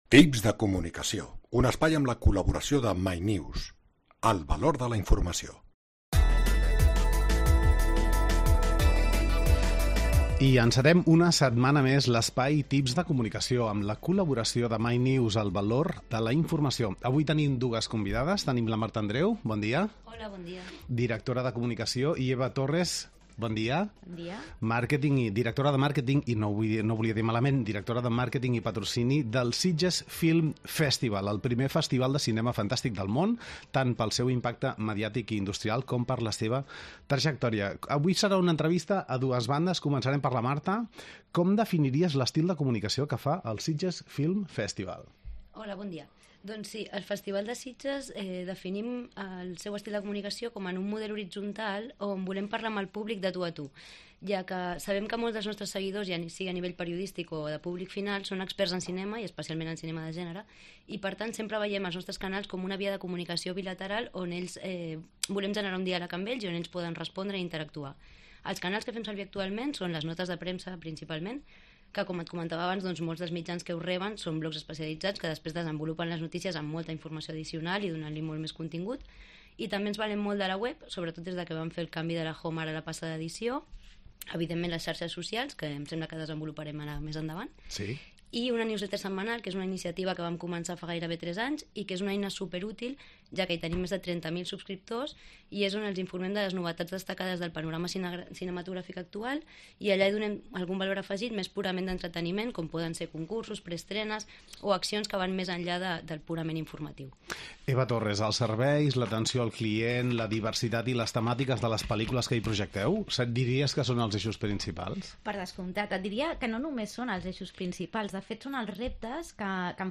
És l'entrevistada d'aquesta setmana